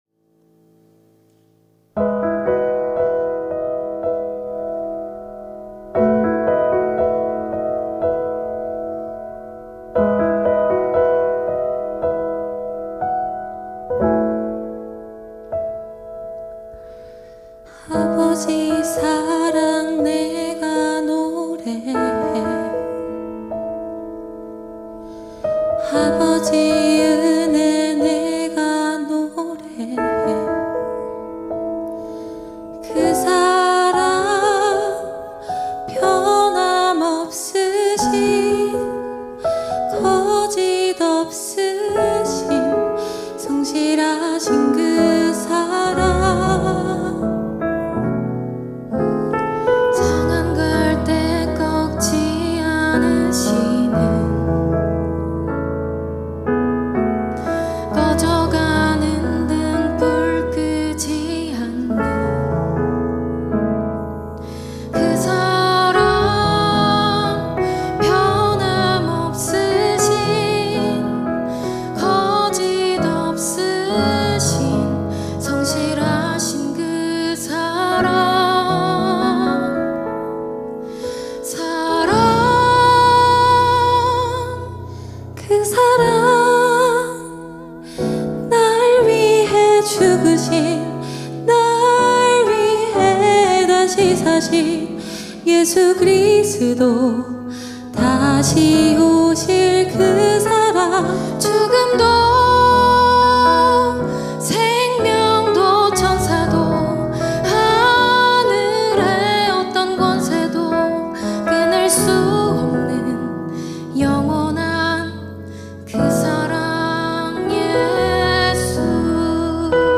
특송과 특주 - 그 사랑